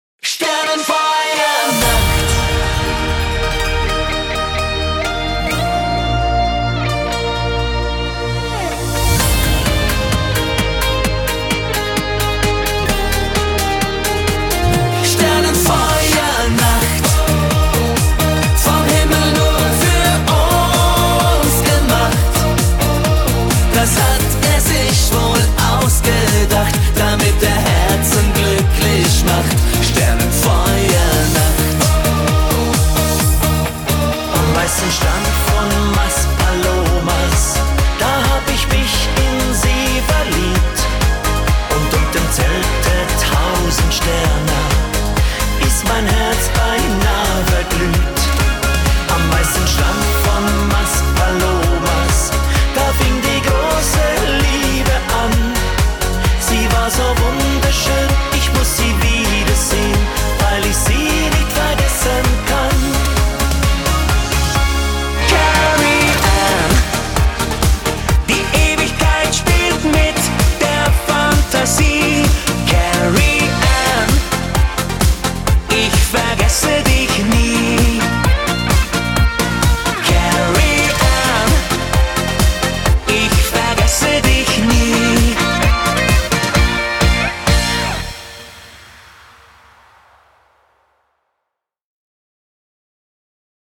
Musikproduktion